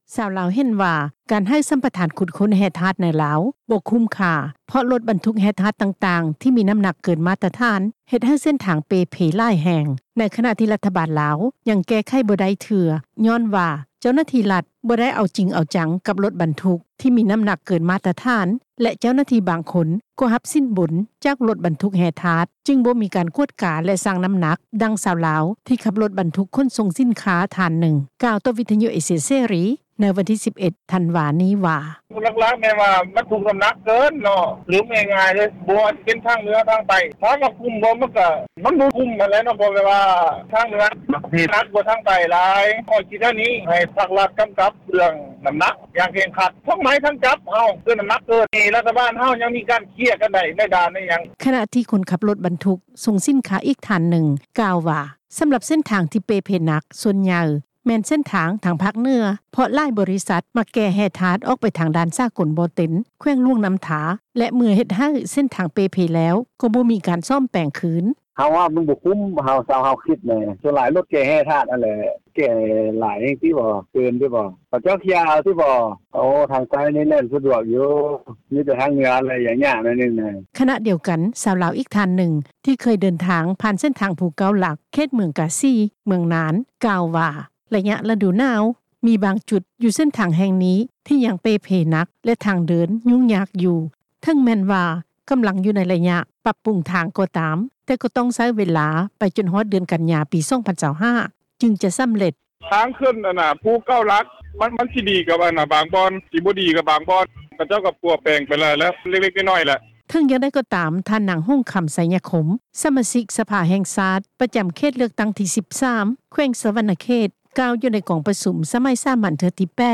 ຊາວລາວ ເຫັນວ່າ ການໃຫ້ສໍາປະທານຂຸດຄົ້ນແຮ່ທາດໃນລາວ ບໍ່ຄຸ້ມຄ່າ ເພາະລົດບັນທຸກແຮ່ທາດຕ່າງໆ ທີ່ນ້ຳໜັກເກີນມາດຕະຖານ ເຮັດໃຫ້ເສັ້ນທາງເປ່ເພຫຼາຍແຫ່ງ ໃນຂະນະທີ່ລັດຖະບານລາວ ຍັງແກ້ໄຂບໍ່ໄດ້ເທື່ອ ຍ້ອນວ່າ ເຈົ້າໜ້າທີ່ລັດ ບໍ່ໄດ້ເອົາຈິງເອົາຈັງກັບລົດບັນທຸກ ທີ່ນ້ຳໜັກເກີນມາດຕະຖານ ແລະ ເຈົ້າໜ້າທີ່ບາງຄົນ ກໍຮັບສິນບົນຈາກລົດບັນທຸກແຮ່ທາດ ຈຶ່ງບໍ່ມີການກວດກາ ແລະຊັ່ງນ້ຳໜັກ, ດັ່ງ ຊາວລາວ ທີ່ຂັບລົດບັນທຸກຂົນສົ່ງສິນຄ້າ ທ່ານໜຶ່ງ ກ່າວຕໍ່ວິທຍຸເອເຊັຽເສຣີ ໃນວັນທີ 11 ທັນວາ ນີ້ວ່າ:
ຂະນະທີ່ ຄົນຂັບລົດບັນທຸກສົ່ງສິນຄ້າ ອີກທ່ານໜຶ່ງ ກ່າວວ່າ ສໍາລັບເສັ້ນທາງທີ່ເປ່ເພໜັກ ສ່ວນໃຫຍ່ ແມ່ນເສັ້ນທາງທາງພາກເໜືອ ເພາະຫຼາຍບໍລິສັດ ມັກແກ່ແຮ່ທາດອອກໄປທາງດ່ານສາກົນບໍ່ເຕັນ ແຂວງຫຼວງນ້ຳທາ ແລະເມື່ອເຮັດໃຫ້ເສັ້ນທາງເປ່ເພແລ້ວ ກໍບໍ່ມີການສ້ອມແປງຄືນ: